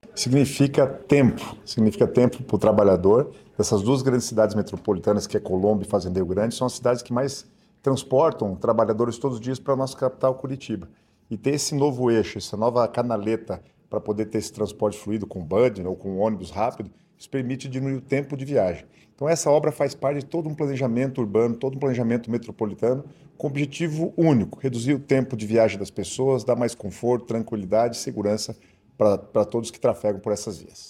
Sonora do secretário das Cidades, Guto Silva, sobre o anúncio de corredor de ônibus para conectar Colombo, Curitiba e Fazenda Rio Grande | Governo do Estado do Paraná